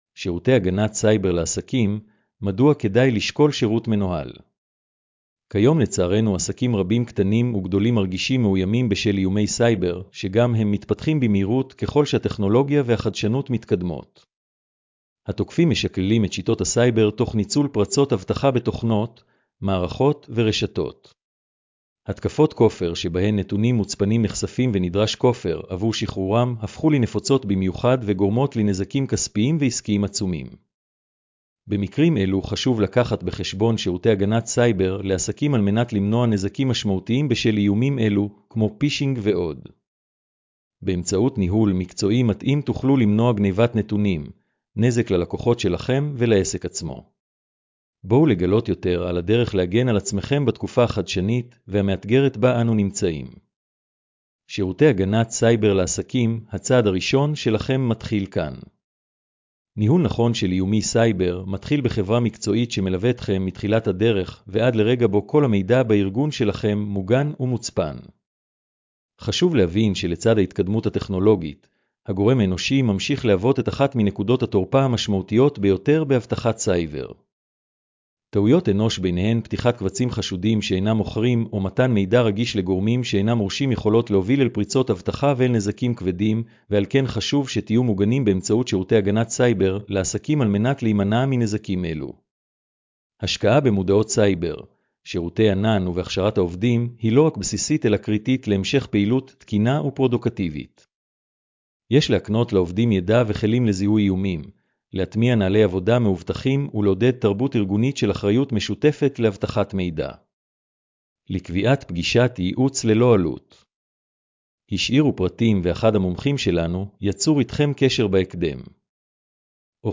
הקראת המאמר לאנשים עם מוגבלות: